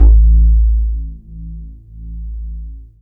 bseTTE48005moog-A.wav